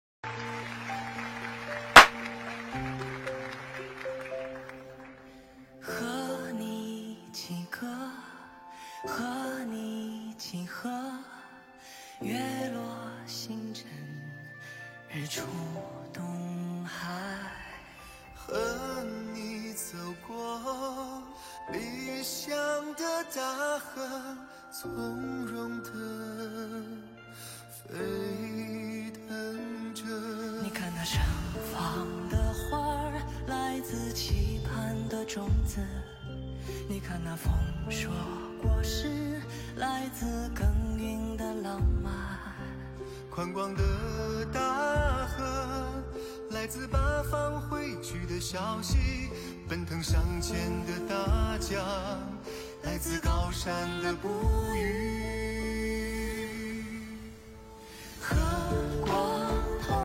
音频：温哥华观音堂大年初一团拜会！2023年01月22日